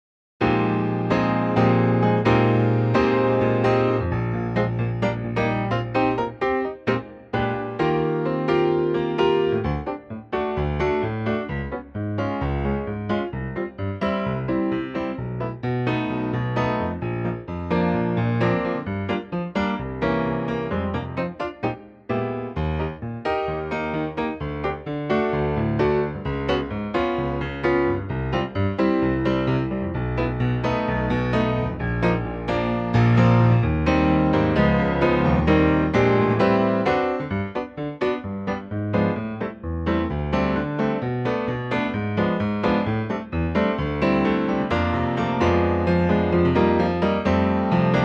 key - Eb - vocal range - Bb to G (optional Bb top note)